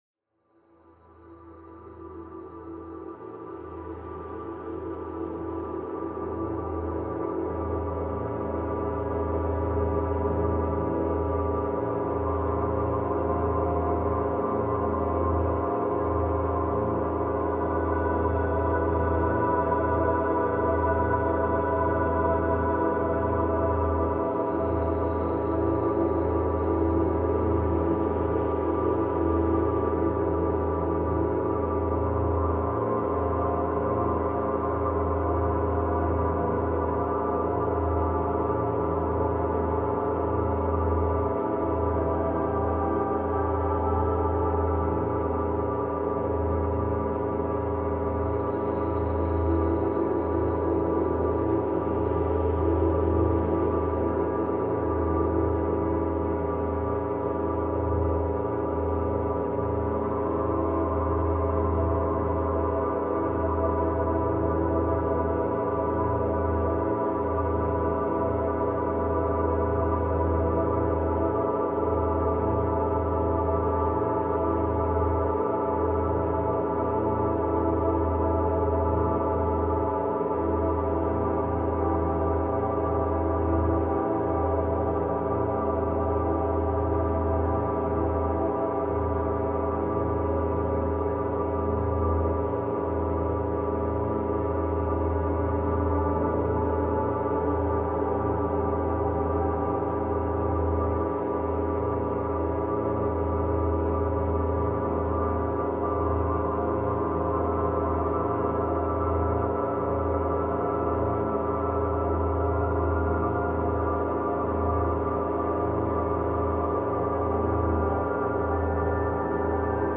Minuteur 528 Hz : Focus Profond pour Objectifs